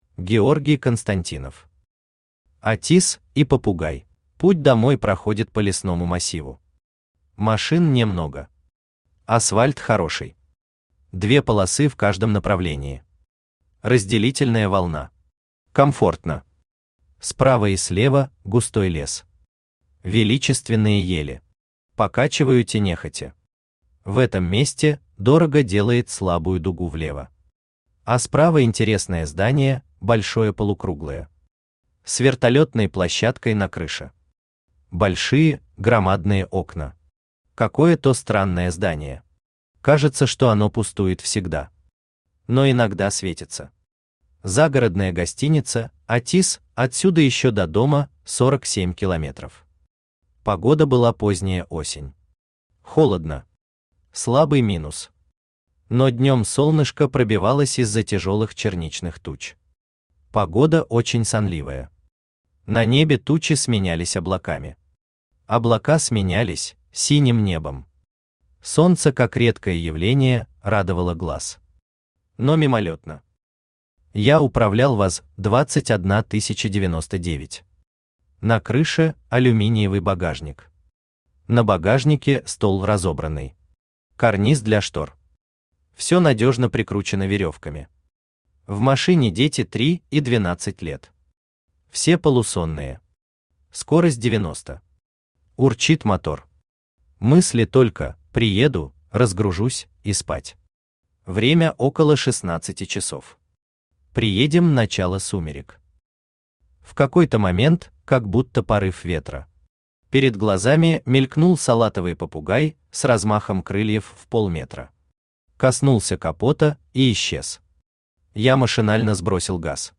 Аудиокнига «Атис» и попугай | Библиотека аудиокниг
Aудиокнига «Атис» и попугай Автор Георгий Константинов Читает аудиокнигу Авточтец ЛитРес.